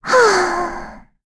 Jane-Vox_Sigh1.wav